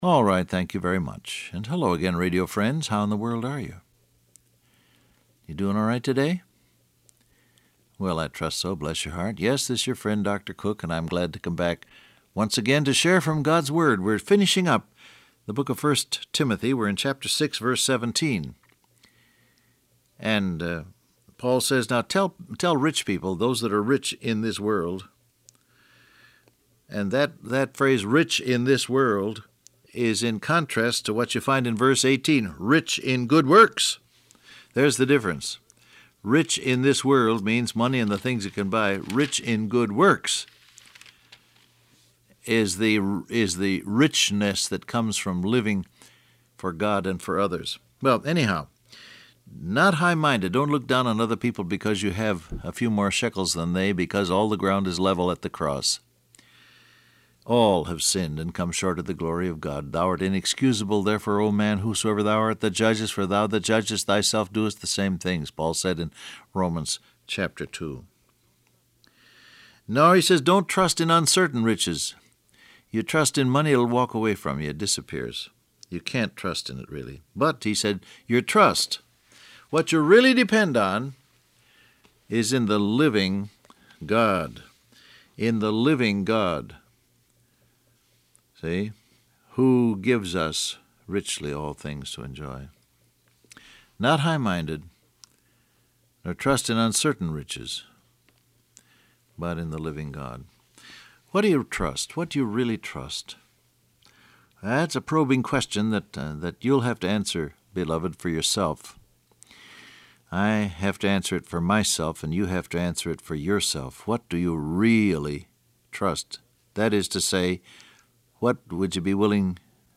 Download Audio Print Broadcast #6774 Scripture: 1 Timothy 6:17-18 , Romans 2 Topics: Sharing , Helpful , Enjoyment Transcript Facebook Twitter WhatsApp Alright, thank you very much.